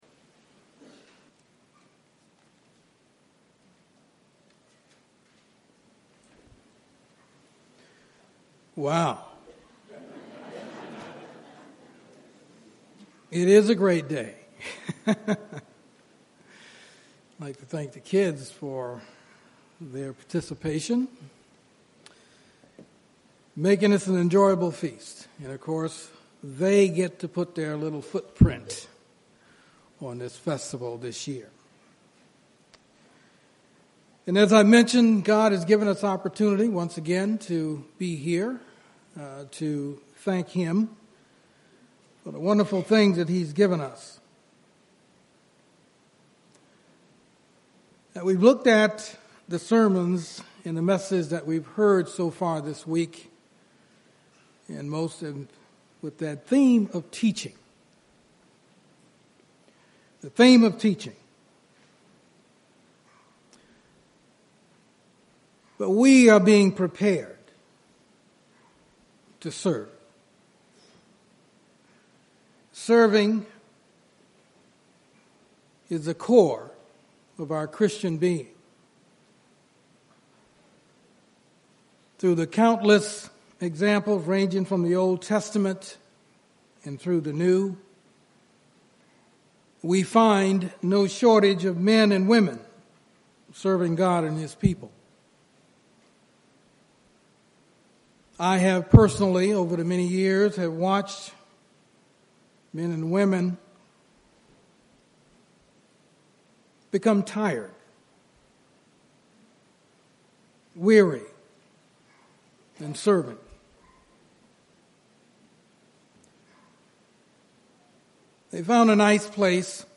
This sermon was given at the Phoenix, Arizona 2017 Feast site.